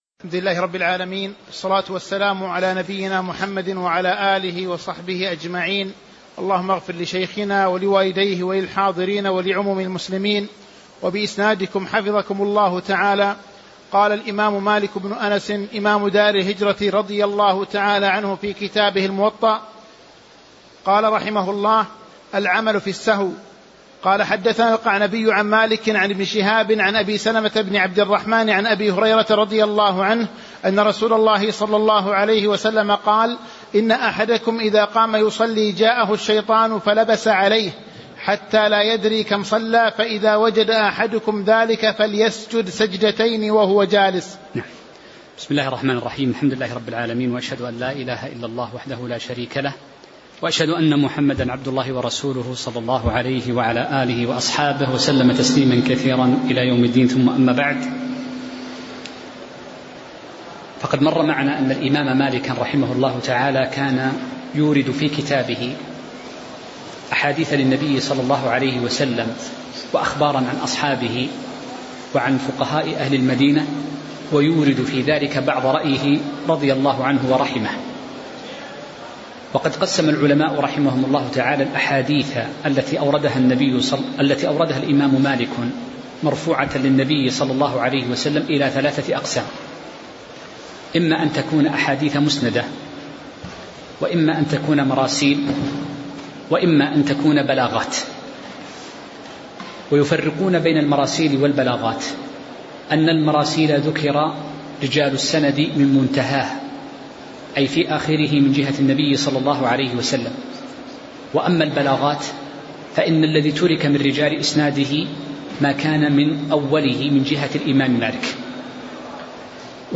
تاريخ النشر ٢٣ محرم ١٤٤٦ هـ المكان: المسجد النبوي الشيخ